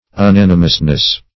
U*nan"i*mous*ness, n.